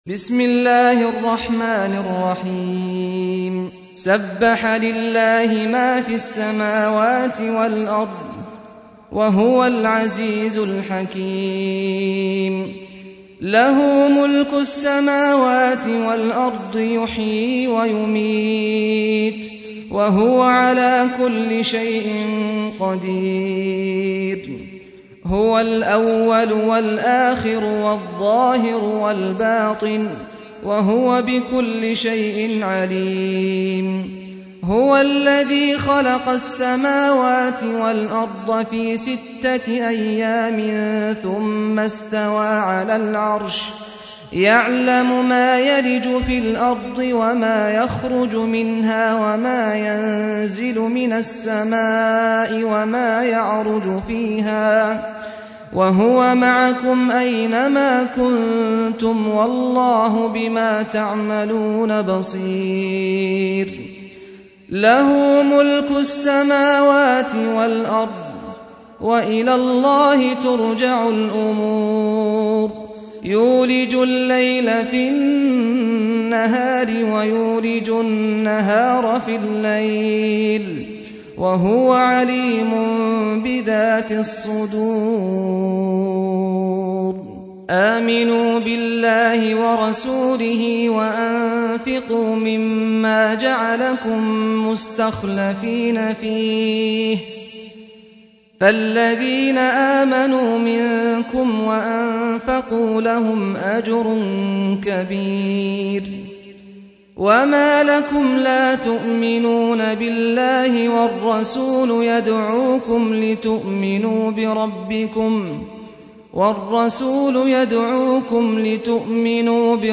سُورَةُ الحَدِيدِ بصوت الشيخ سعد الغامدي